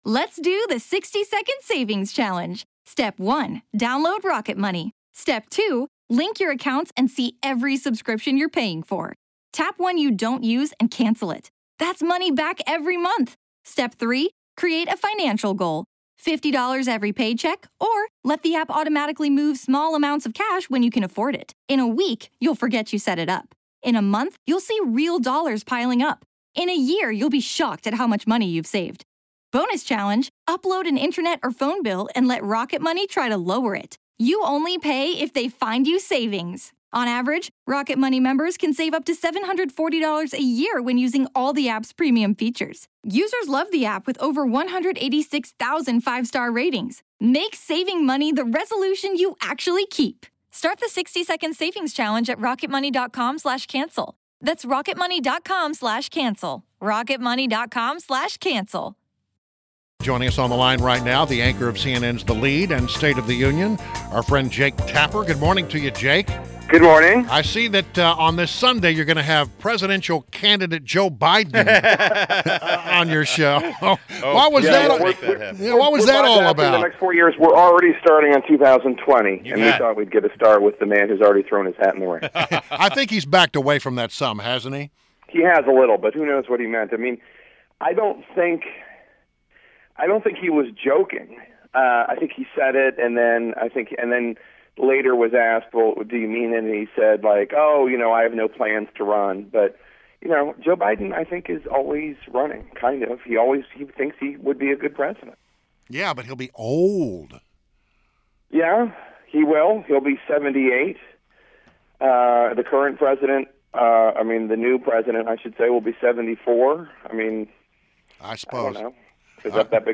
WMAL Interview - JAKE TAPPER - 12.08.16